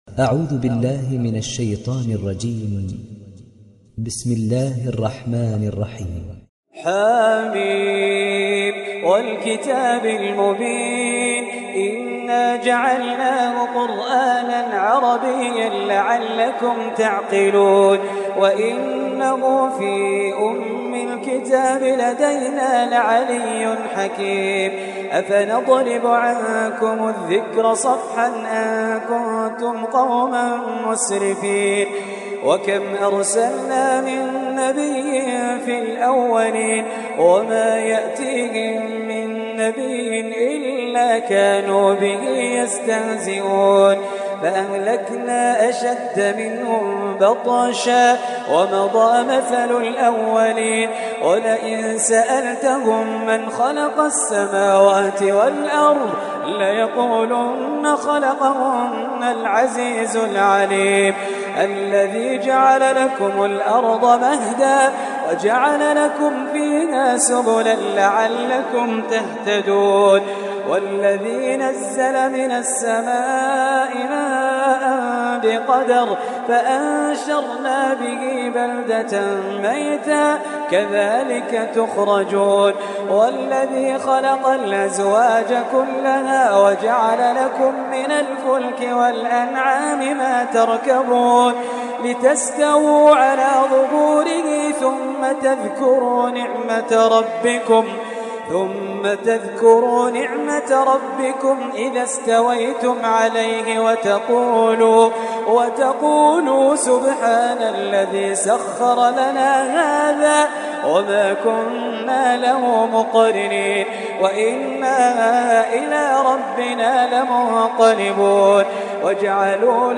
تحميل سورة الزخرف mp3 بصوت خالد الجليل برواية حفص عن عاصم, تحميل استماع القرآن الكريم على الجوال mp3 كاملا بروابط مباشرة وسريعة